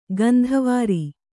♪ gandhavāri